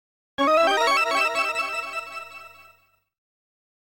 Sound effect